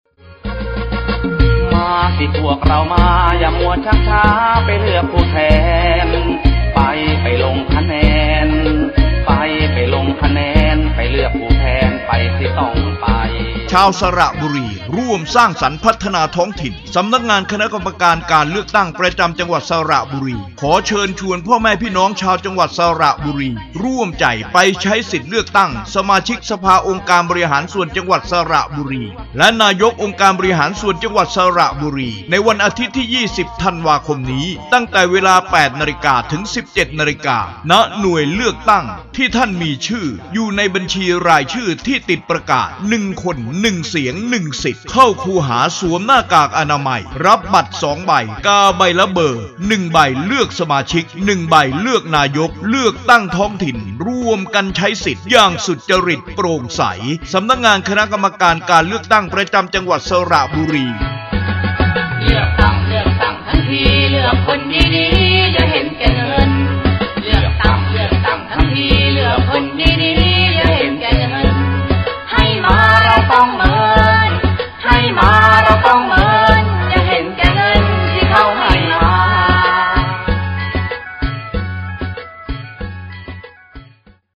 สปอตประชาสัมพันธ์ การเลือกตั้งสมาชิกสภาองค์การบริหารส่วนจังหวัดสระบุรีและนายกองค์การบริหารส่วนจังหวัดสระบุรี 2 – (ไฟล์เสียง MP3)
สปอต_รณรงค์เลือกตั้ง_อบจ_สระบุรี_Vol_2.mp3